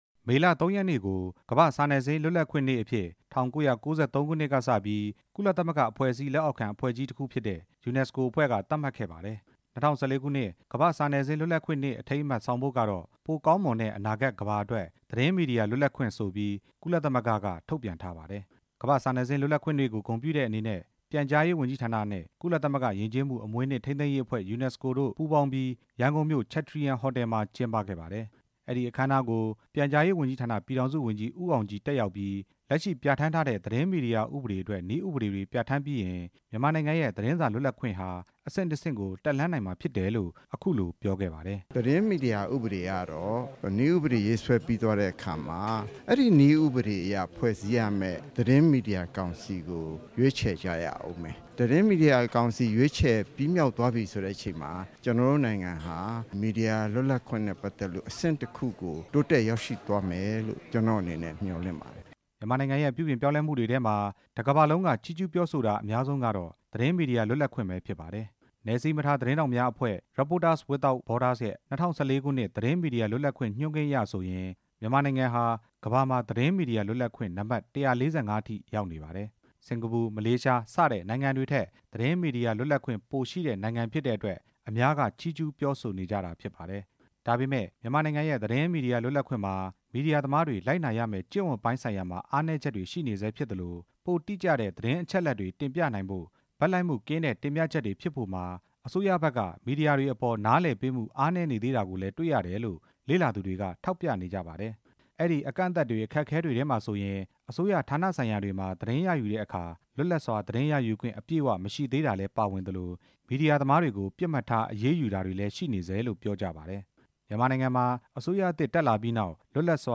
ကမ္ဘာ့စာနယ်ဇင်း လွတ်လပ်ခွင့်နေ့အကြောင်း တင်ပြချက်